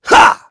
Clause_ice-Vox_Attack6.wav